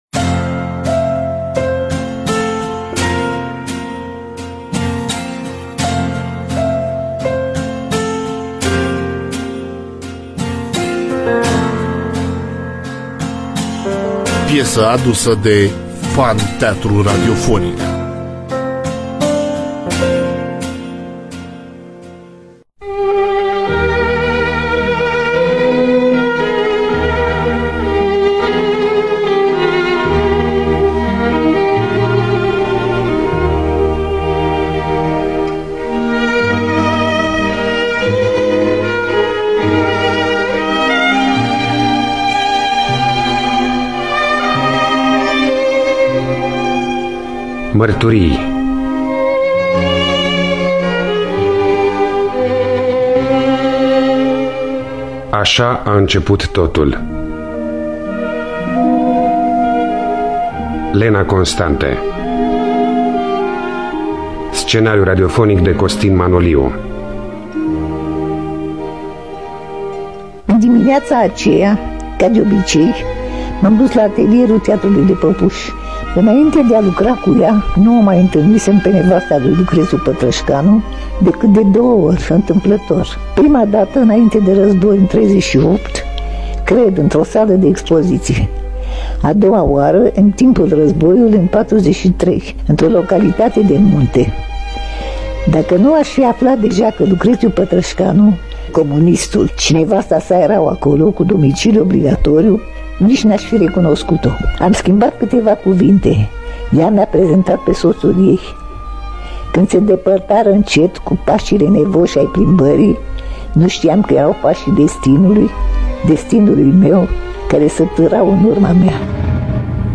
Scenariu radiofonic de Ion-Costin Manoliu. Cu participarea extraordinară a Lenei Constante.